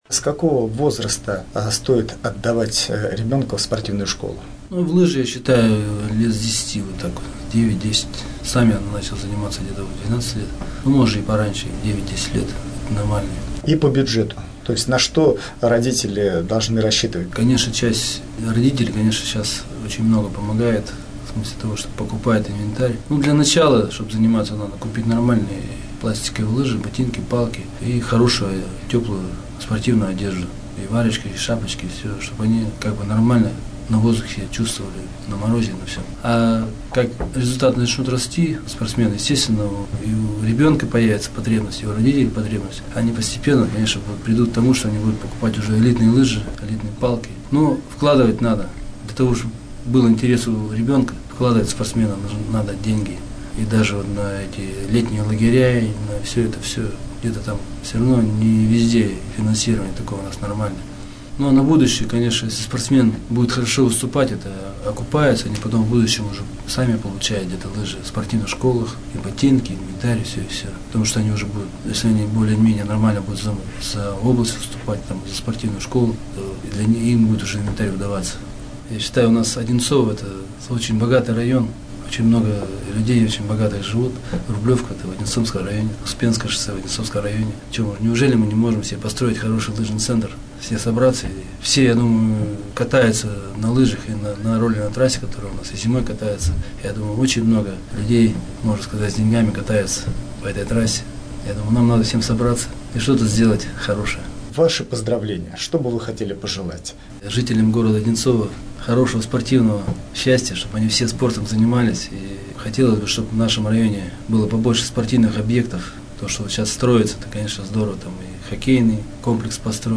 В интервью он признался, что до сих пор переживает, вспоминая тот момент в Калгари.